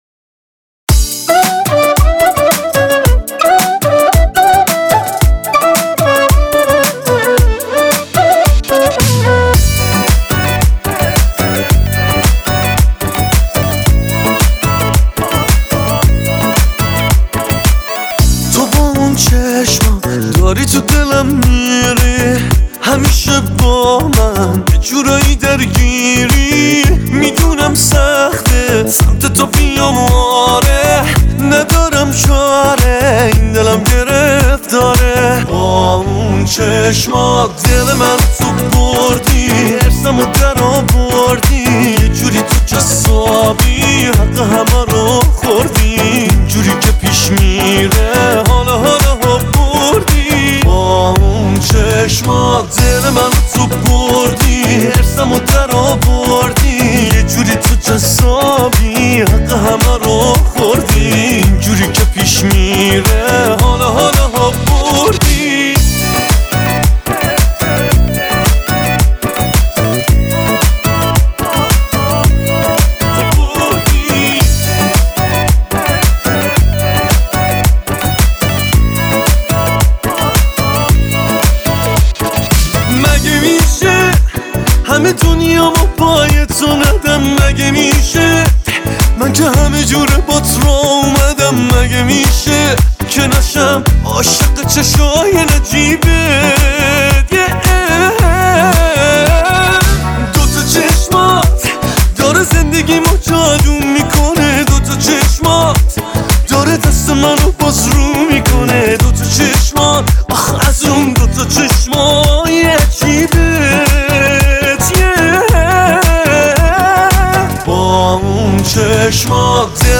Sing: Pop - آواز: پاپ